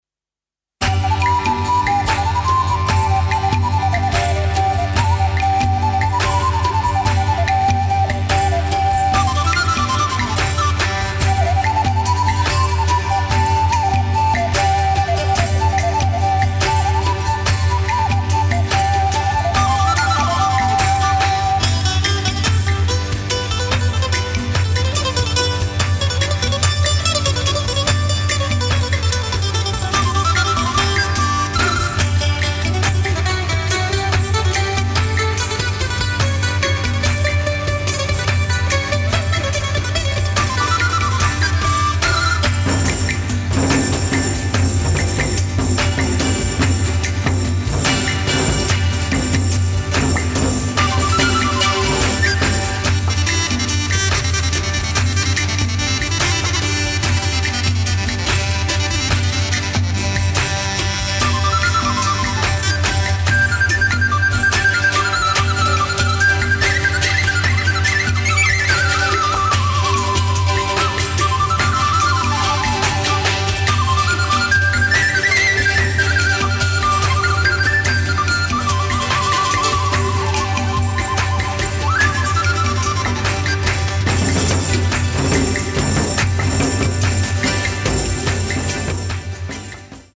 original motion picture score